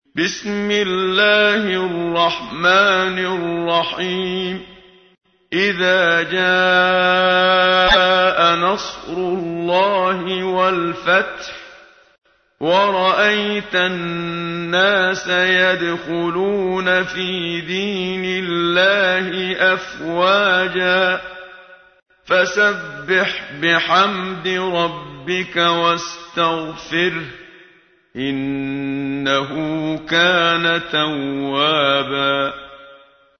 تحميل : 110. سورة النصر / القارئ محمد صديق المنشاوي / القرآن الكريم / موقع يا حسين